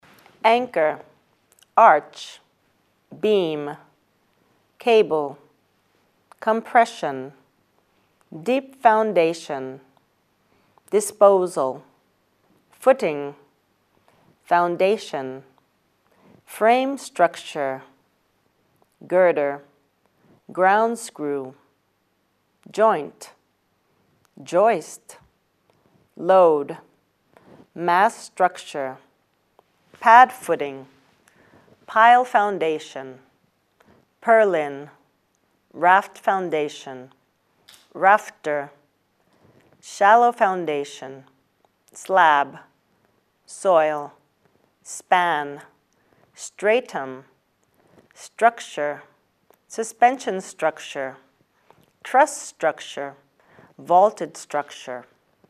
Terms to study and audio with the pronunciation